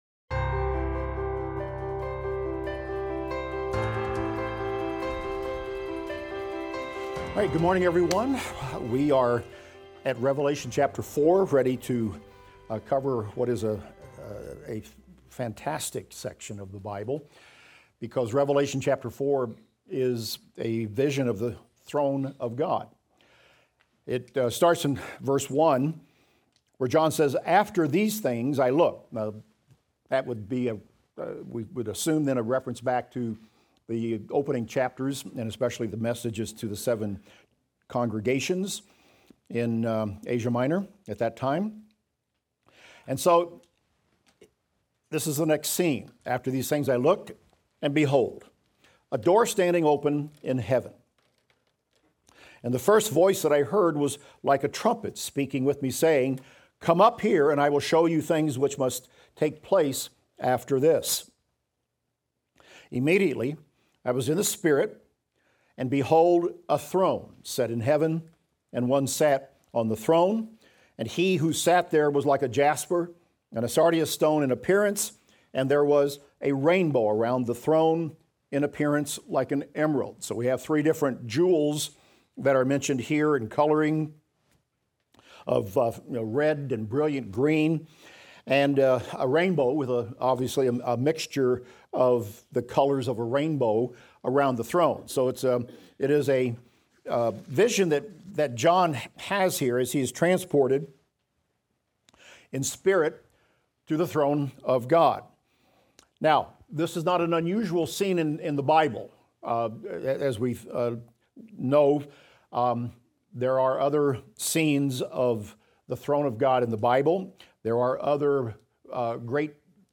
Revelation - Lecture 35 - audio.mp3